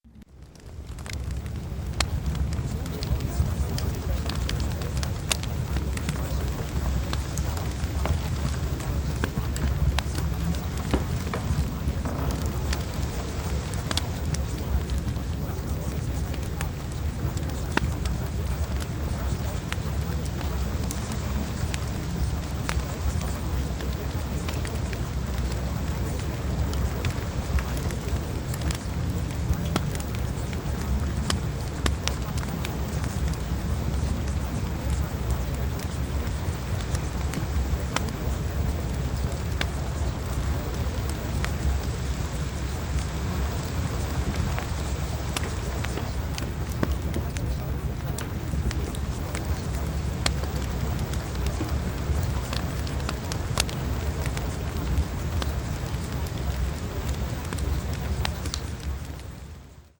LUXURY-LIFESTYLE-Fireplace-Sample.mp3